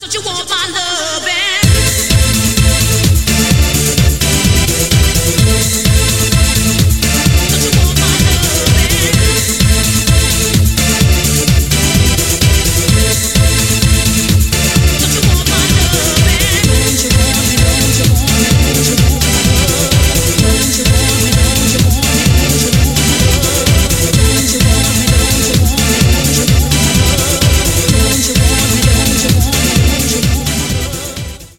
• Качество: 128, Stereo
Техно